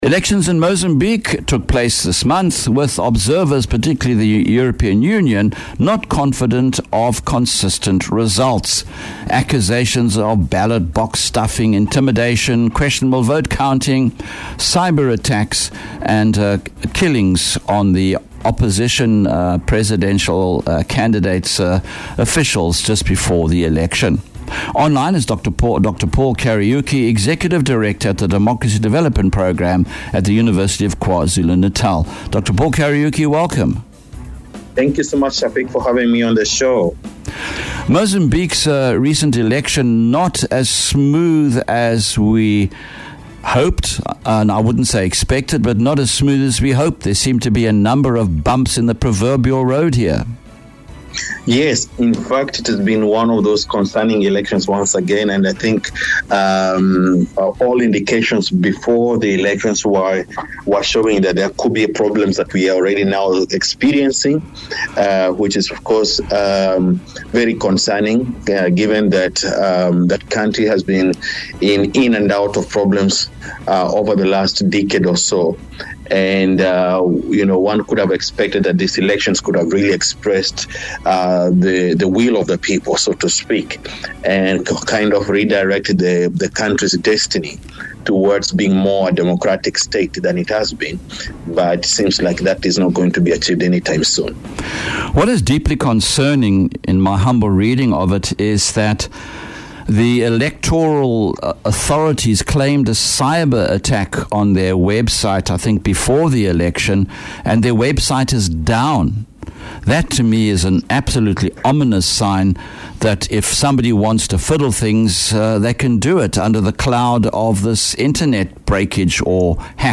Western Cape’s No.1 Community Radio Station